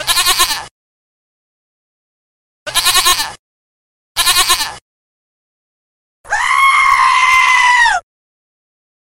Kategorie Zwierzęta